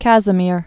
(kăzə-mîr, kăs-)